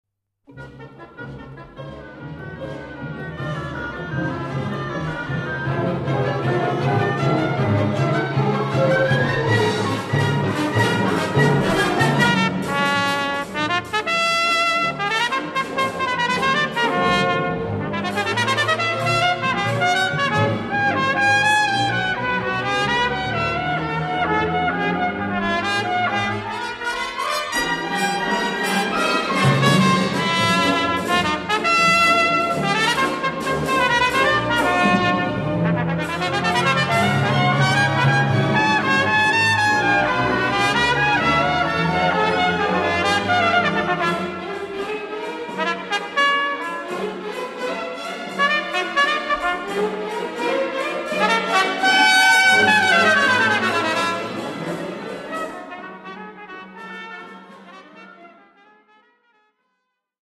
Voicing: Orchestra